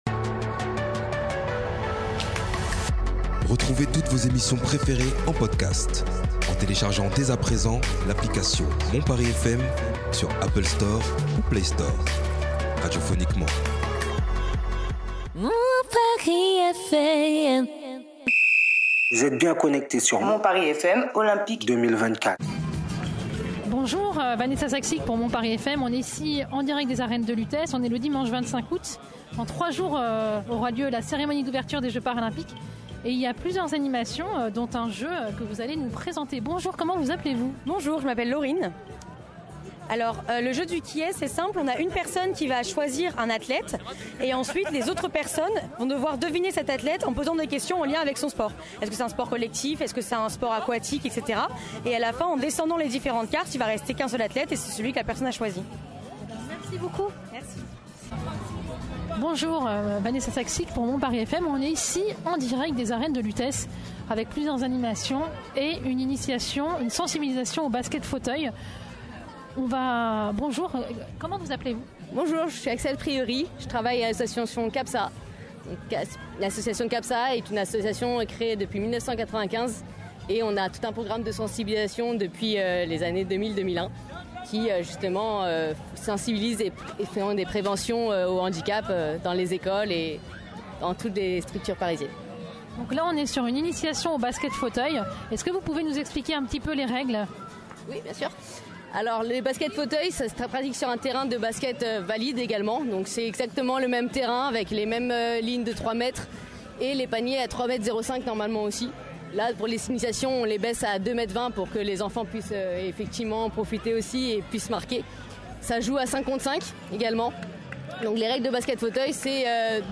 Interview ; Les Arènes de Lutece
Ambiance du tonnerre aux Arènes de Lutèce en ce 25 Août 2024.
Sur la scène, prise de parole de Madame la Maire du 5ème arrondissement, Florence BERTHOUT en présence de para-athlètes, du Comité Régional Handisport. Intervention de Pascal GENTIL, l'ancien grand champion de Taekwendo et de Pierre RABADAN, Adjoint de la Maire de Paris, en charge du sport, des JOP et de la Seine.